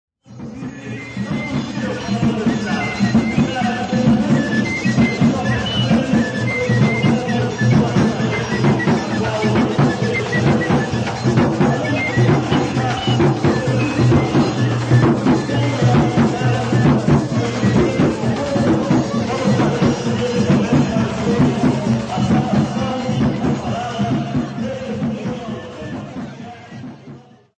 O batuque, também conhecido como tambu ou samba de umbigada, e o samba lenço, aqui apresentados, são formas de expressão afro-brasileiras.
Os registros aqui apresentados mantêm a estrutura das festas em que ocorreram, isto é, antes da dança coletiva, o acompanhamento de procissões, terço com rezas cantadas, vivas… para depois começar o samba. Nos intervalos, ora estão ensaiando novos sambas, ora cantando modas do batuque.